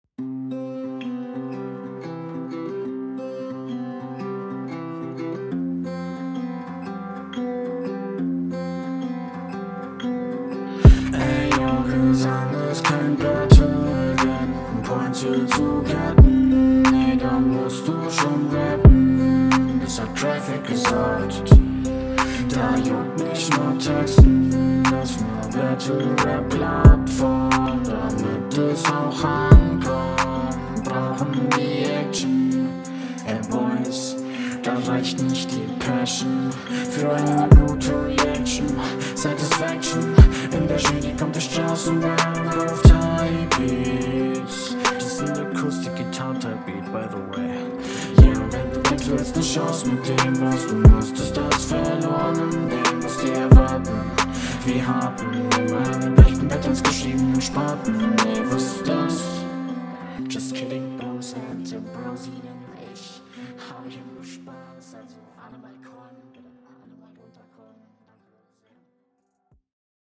Ok das klingt ziemlich cool, ich mag die hohen Doubles, der Beat ist auch schön …
Hier ist leider kaum etwas zu verstehen.. höre zwischendurch immer nur einzelne Wörter raus.. :( …
Flow: Stimmeinsatz ist an sich gut, nur versteht man nix.